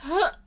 peach_euuh.ogg